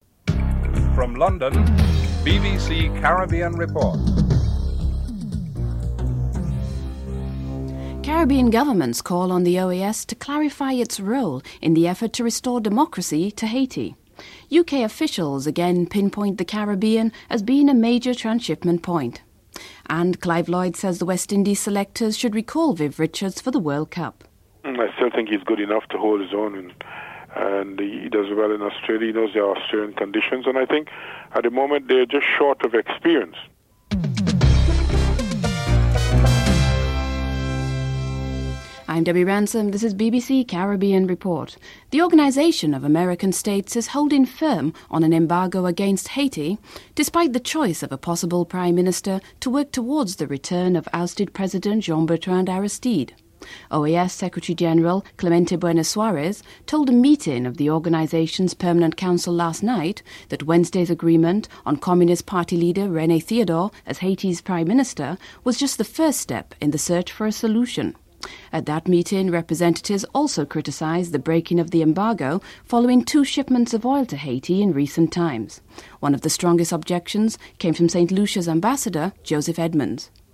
1. Headlines (00:00-00:41)
3. Airing of a speech by Joseph Edmunds, St. Lucia's embassador to the OAS on the embargo against Haiti (01:21-03:41)